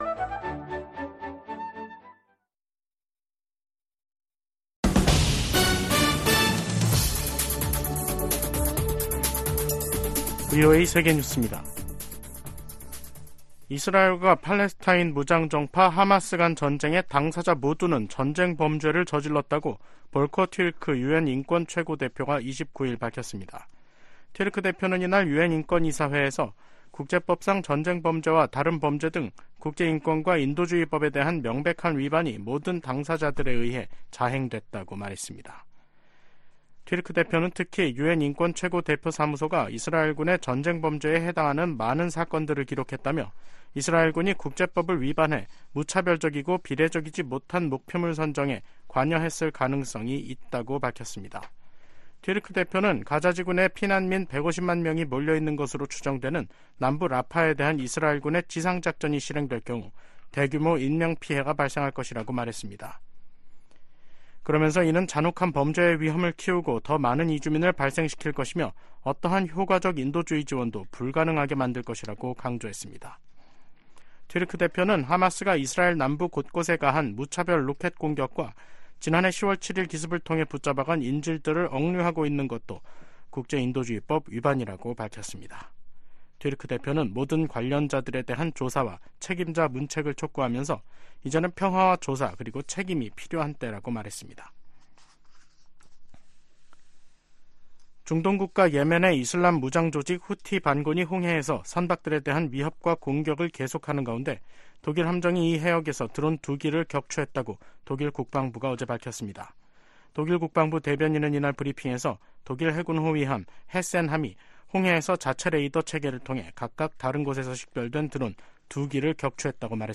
VOA 한국어 간판 뉴스 프로그램 '뉴스 투데이', 2024년 2월 29일 3부 방송입니다. 미국과 한국의 외교장관들이 워싱턴 D.C.에서 만나 세계의 거의 모든 도전에 공조하는 등 양국 협력이 어느 때보다 강력하다고 평가했습니다. 북한이 유엔 군축회의에서 국방력 강화 조치는 자위권 차원이라며 비난의 화살을 미국과 동맹에게 돌렸습니다. 세계 최대 식품 유통업체가 북한 강제 노동 동원 의혹을 받고 있는 중국 수산물 가공 업체 거래를 전격 중단했습니다.